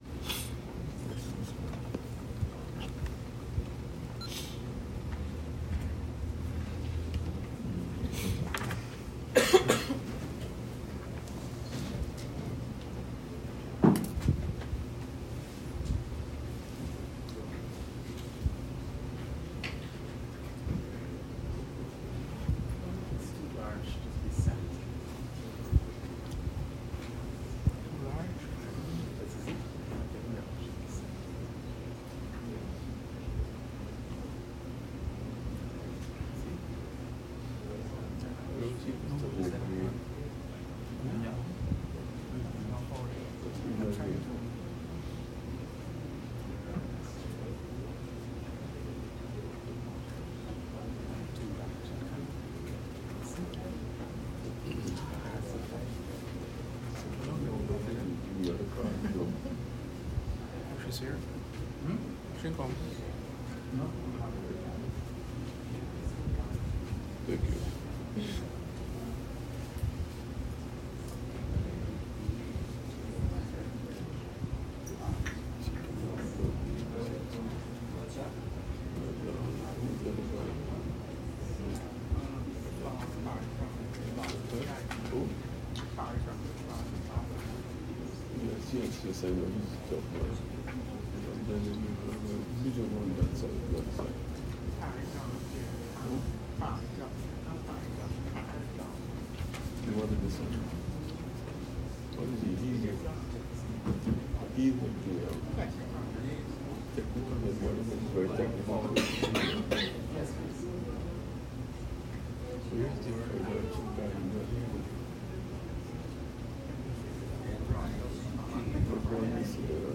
Hearing Notice for Friday – 27 March 2026.